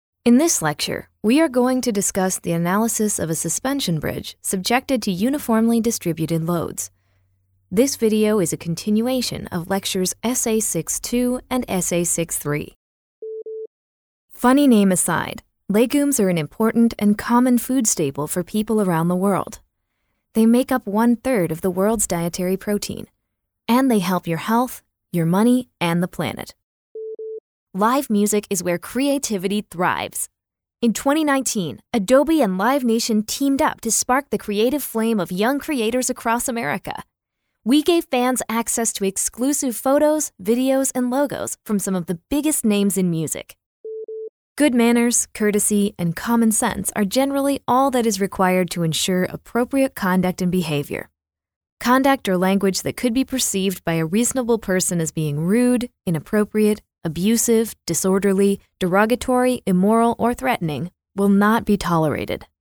Female Voice Over, Dan Wachs Talent Agency.
Current, Modern, Young Mom, Heartfelt.
eLearning